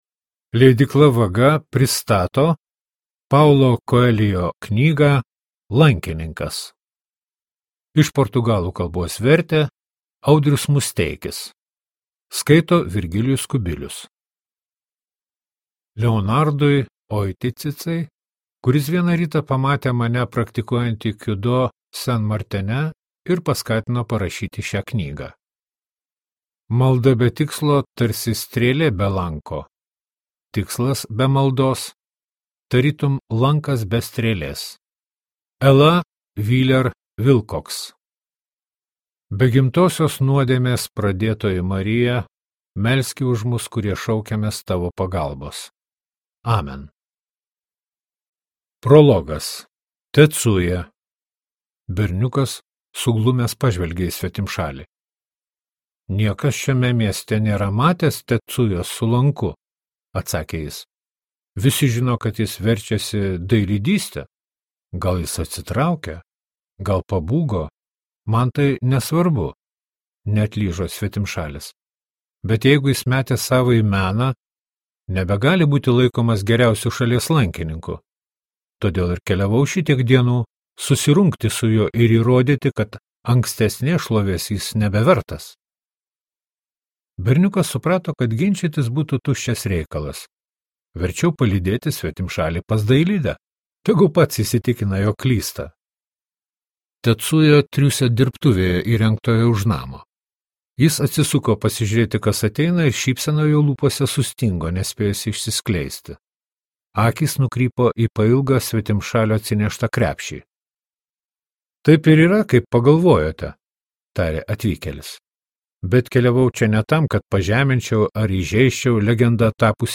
Lankininkas | Audioknygos | baltos lankos